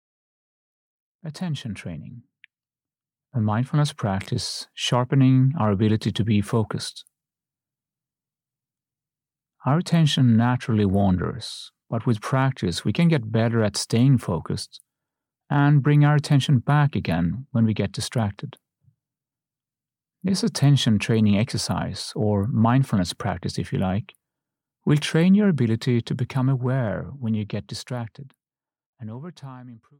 Attention Training (EN) audiokniha
Ukázka z knihy